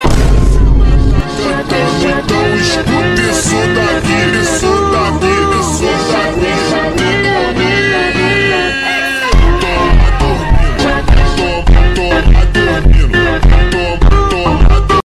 Horror Funk